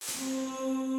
Still getting nasty sounds with time stretching.
Here's an audio file rendered directly from HISE. You can hear the distortion at the beginning, this is just by enabling time-stretching for the sampler without changing the ratio.